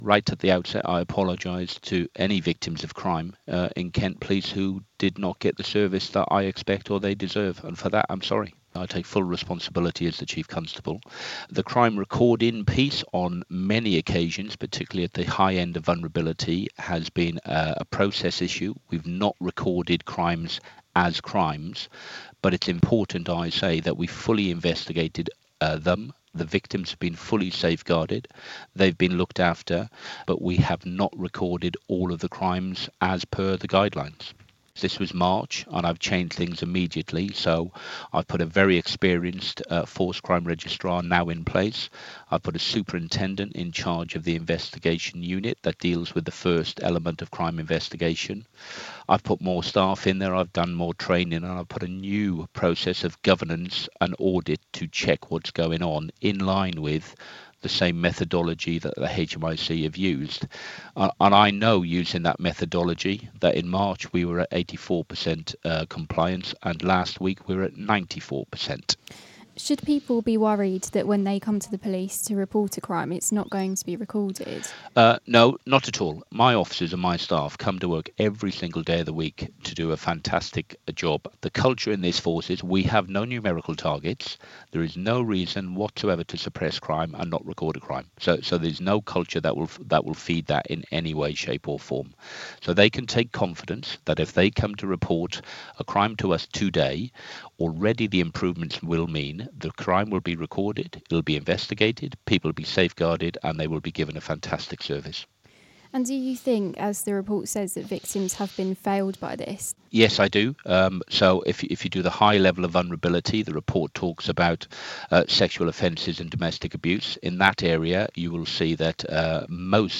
INTERVIEW: Alan Pughsley, Chief Constable of Kent Police - 15/06/2017